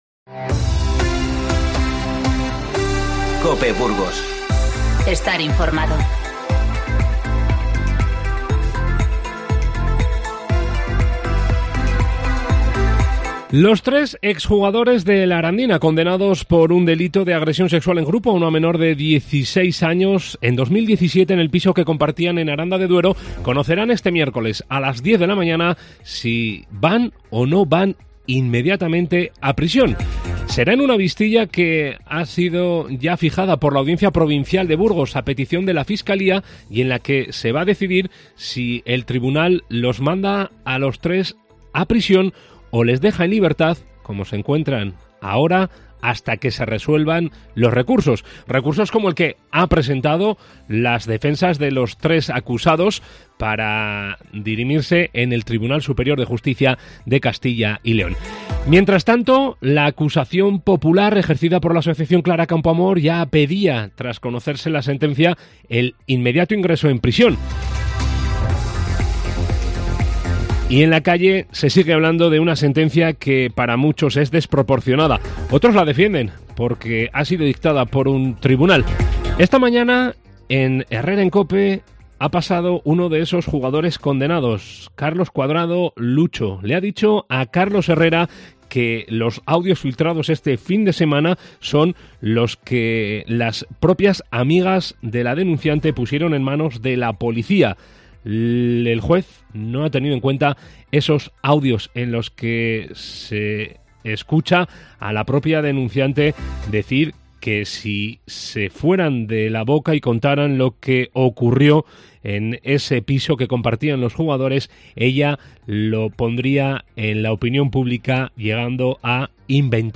INFORMATIVO Mediodía 16-12-19